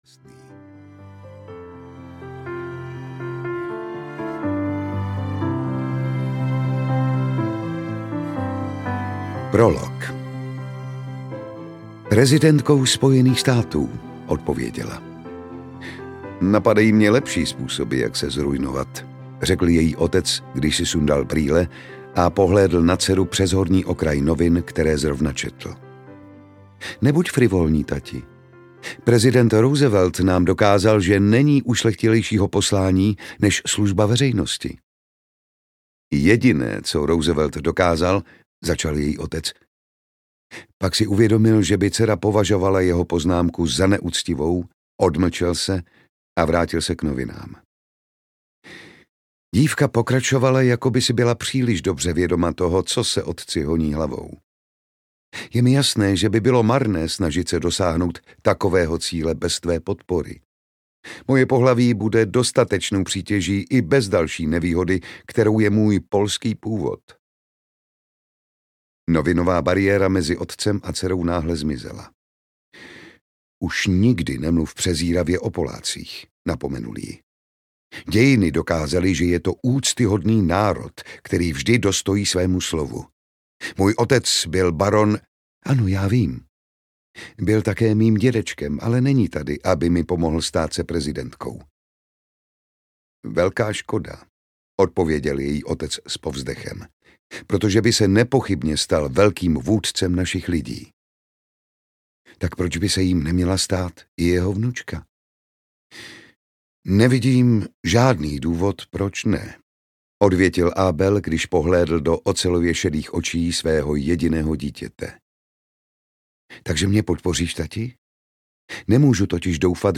Florentýna audiokniha
Ukázka z knihy
• InterpretJan Šťastný
florentyna-audiokniha